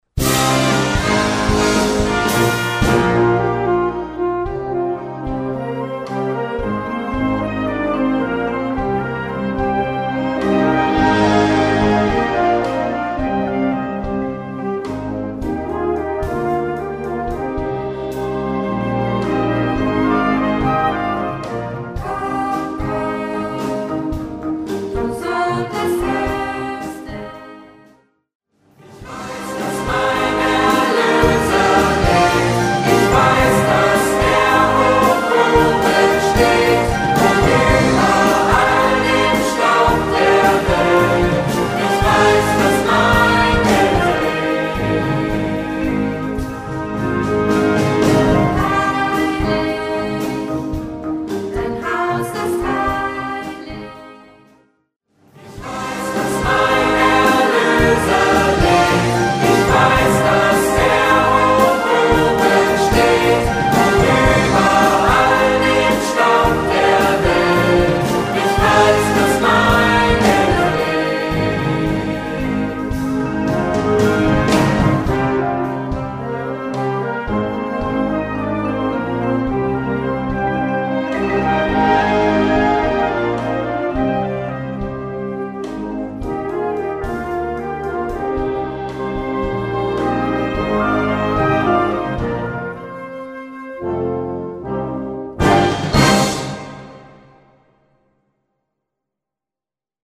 Gattung: Kirchenmusik mit Gesang (ad lib.)
Besetzung: Blasorchester